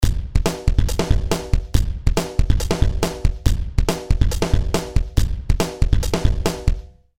The following are mp3 samples that showcase different settings of Danstortion.
Drums
Drums_pos_100.mp3